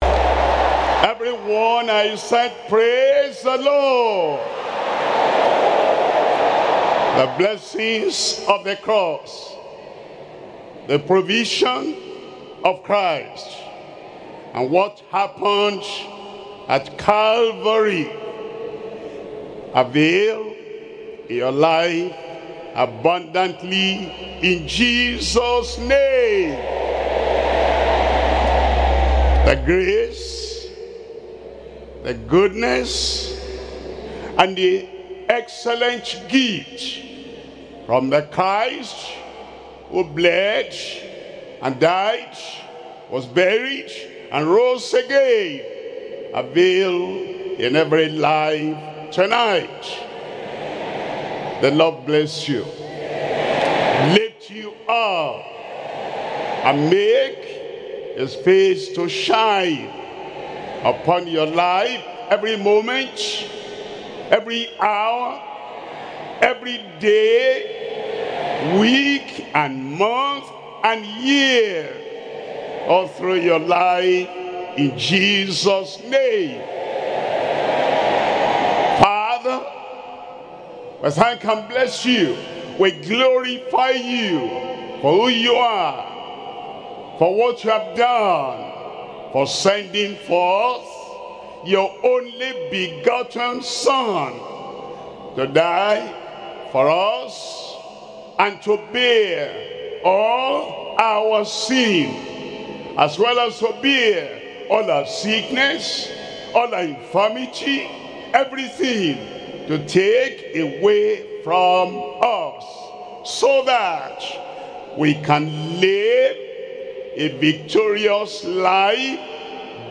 SERMONS – Deeper Christian Life Ministry Australia
2026 Global Easter Retreat